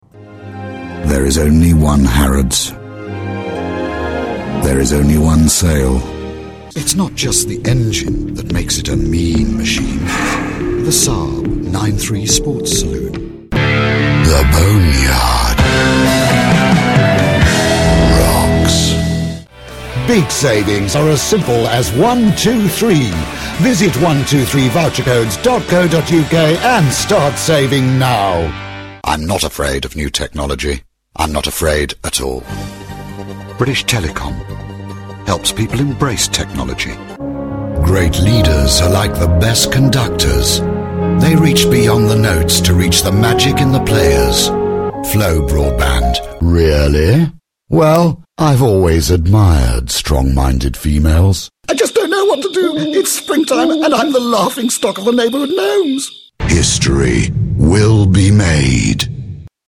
He was given an education that formed his classic English accent - and a voice, deep and warm, that records perfectly and gives that extra edge to any production.
englisch (uk)
Sprechprobe: Werbung (Muttersprache):
A true British voice, warm, friendly & very English.